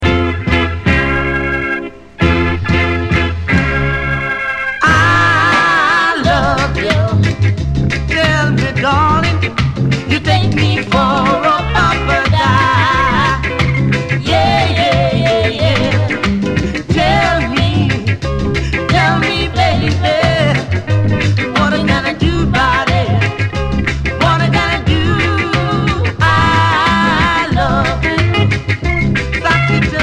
Reggae Ska Dancehall Roots Vinyl ...